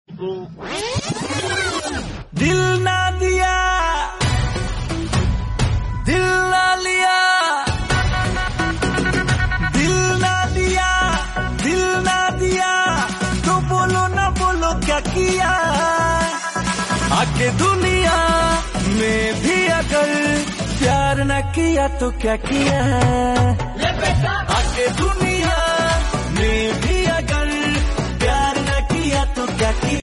Bhojpuri Songs
• Simple and Lofi sound
• Crisp and clear sound